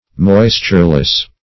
Moistureless \Mois"ture*less\, a. Without moisture.
moistureless.mp3